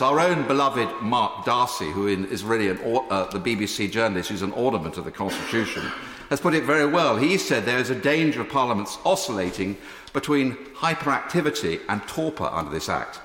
A tribute in the Commons